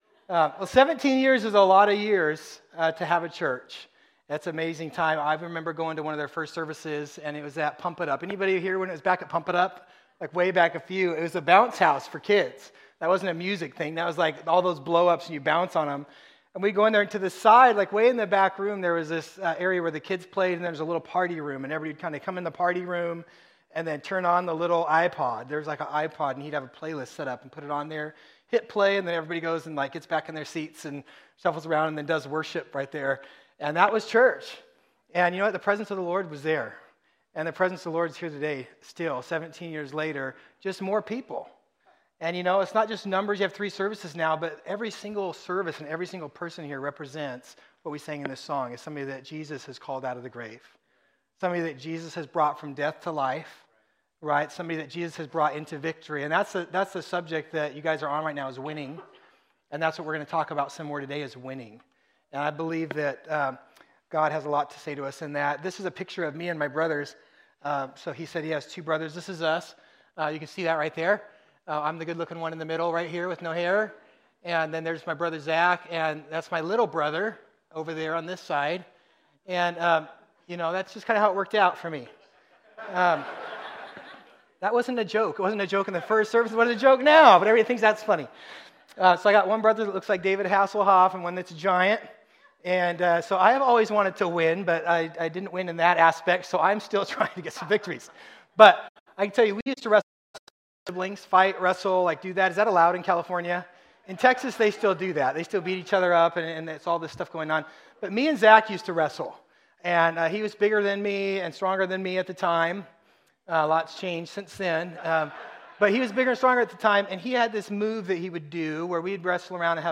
This is Part 2 of our series at Fusion Christian Church on Winning God’s Way.